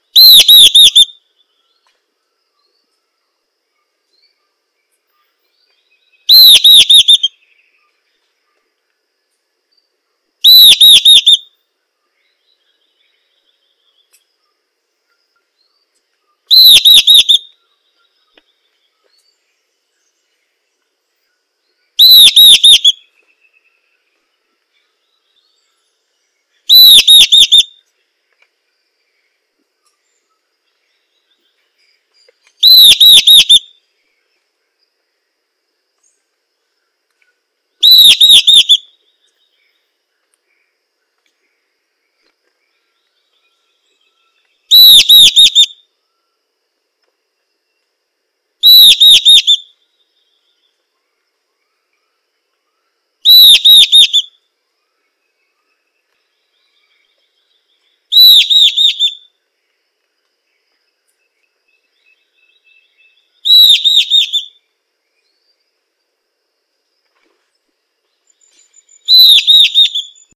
mosquetademonte.wav